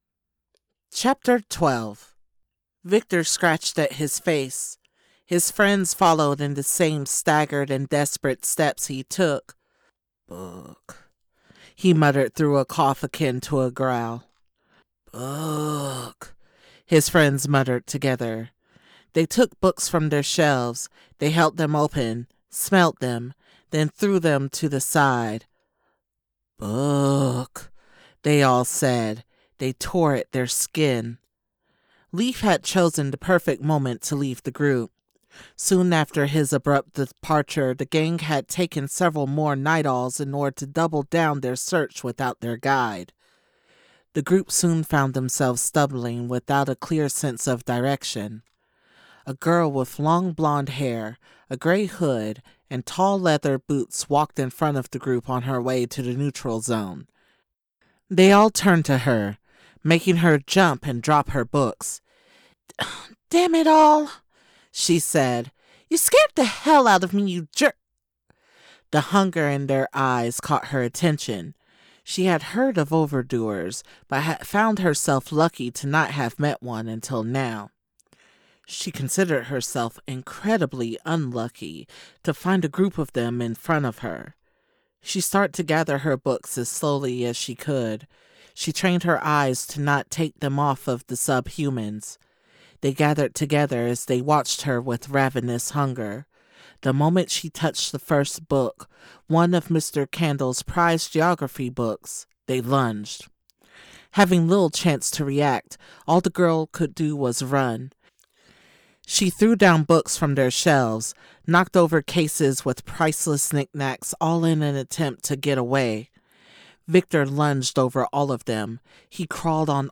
Female
English (North American)
Adult (30-50), Older Sound (50+)
Audiobooks